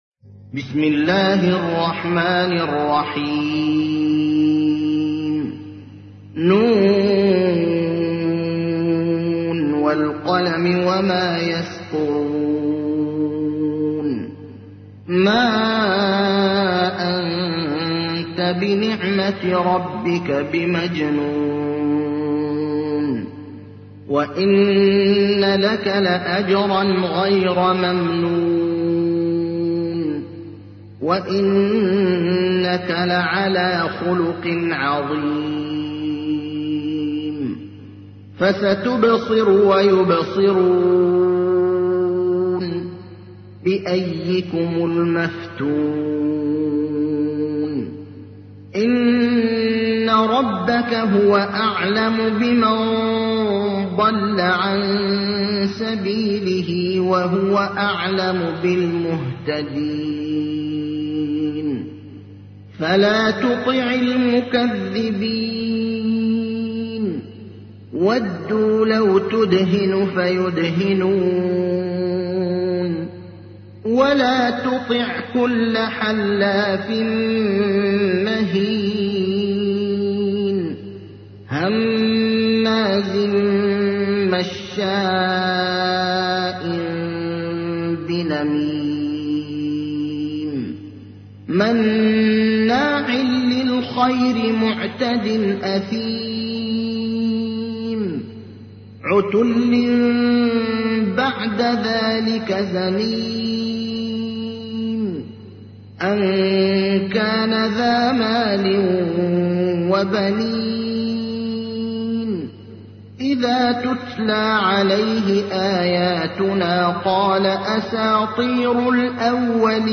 تحميل : 68. سورة القلم / القارئ ابراهيم الأخضر / القرآن الكريم / موقع يا حسين